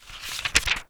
page-flip-6.wav